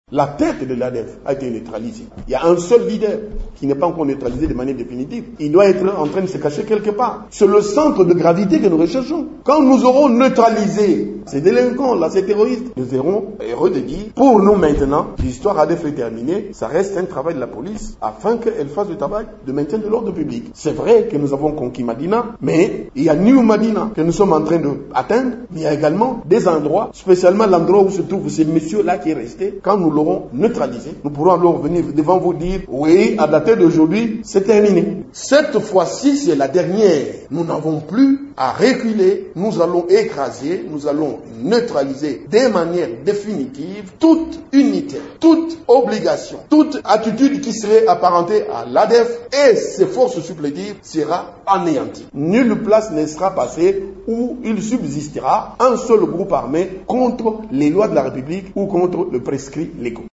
Vous pouvez écouter le général Kasonga dans cet extrait :